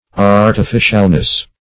Artificialness \Ar`ti*fi"cial*ness\, n.
artificialness.mp3